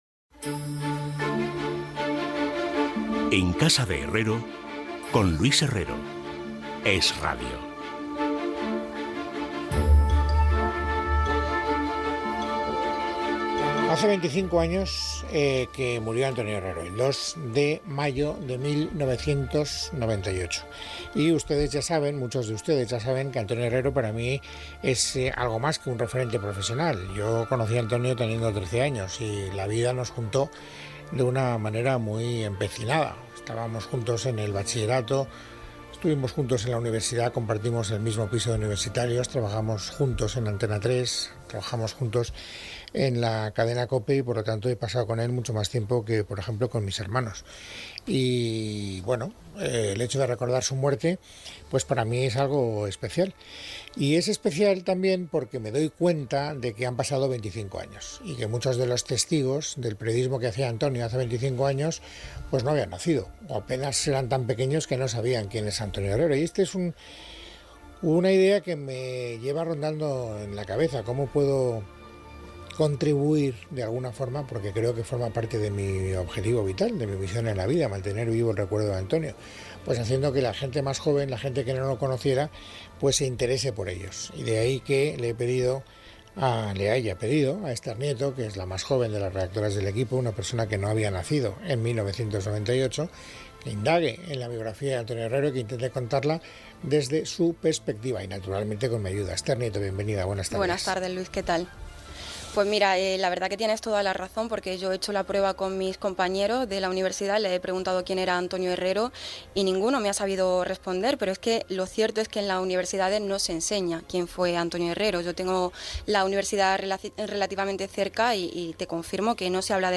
Gènere radiofònic Info-entreteniment Presentador/a Herrero, Luis